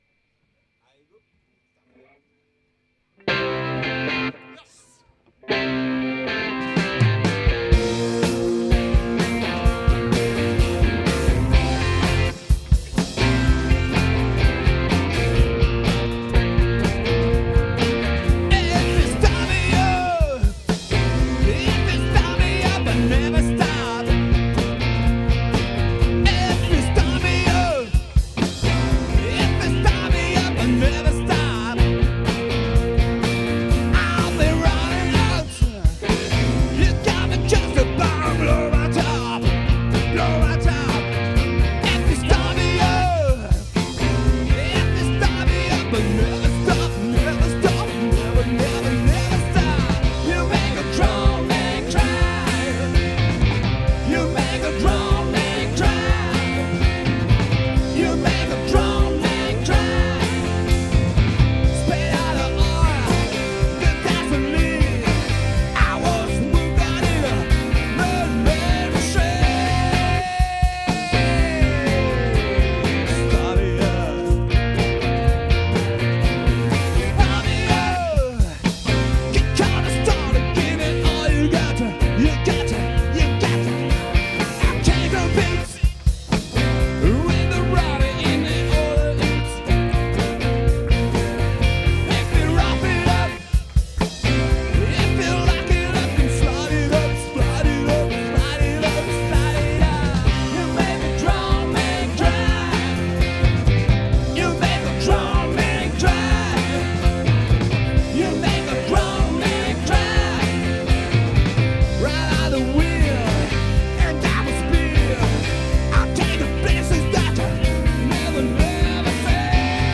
2h30 de Rock, Rockabilly et Rock’n Roll
LIVE Monteux 09.2024
Guitare/Chant/Clavier
Basse/Chant
Batterie